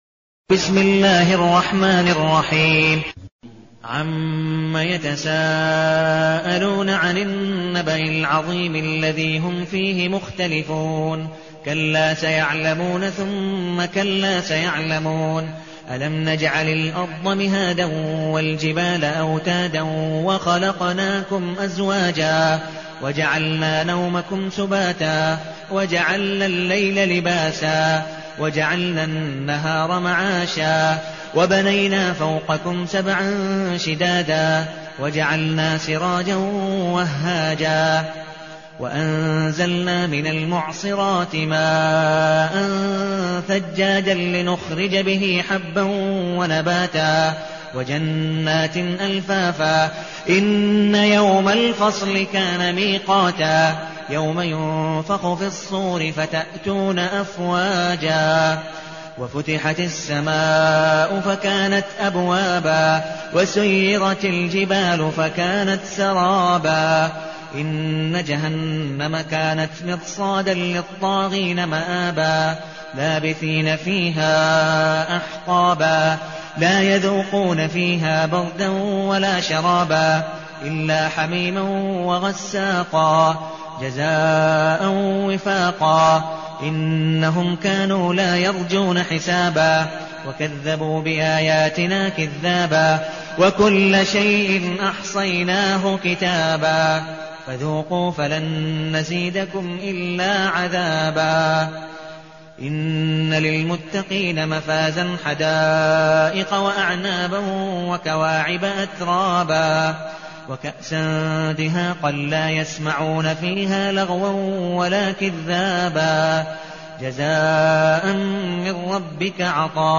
المكان: المسجد النبوي الشيخ: عبدالودود بن مقبول حنيف عبدالودود بن مقبول حنيف النبأ